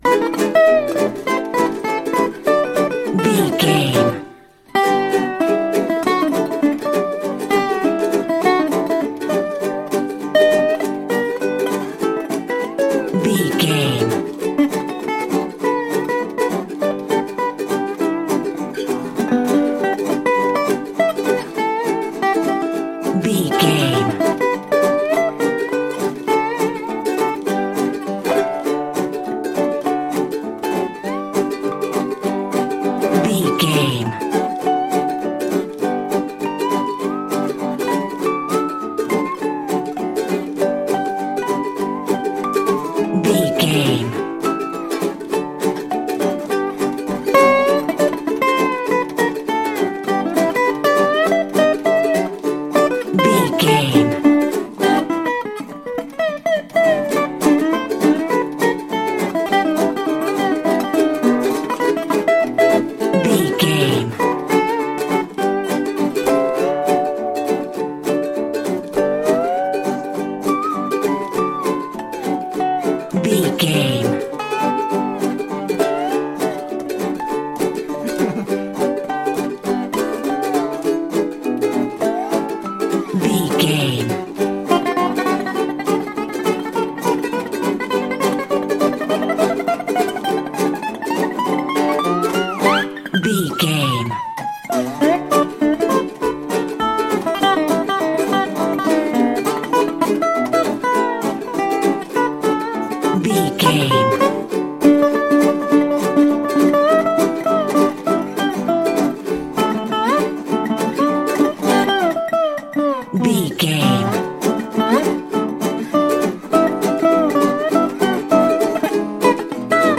Ionian/Major
electric guitar
acoustic guitar
drums
ukulele
slack key guitar